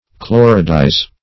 Chloridize \Chlo"rid*ize\, v. t.